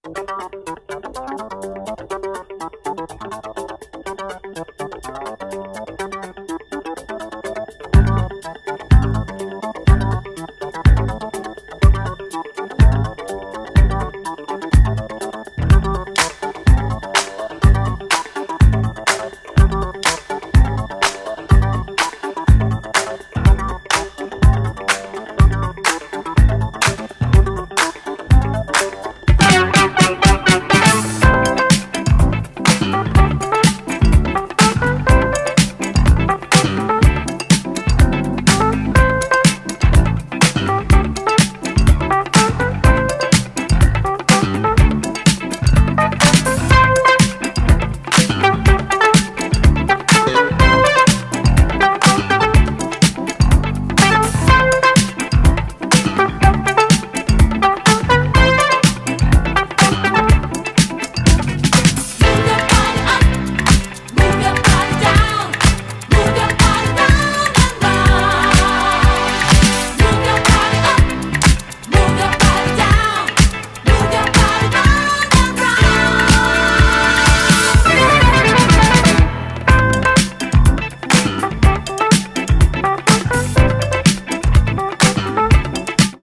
Classic Italo album Remastered!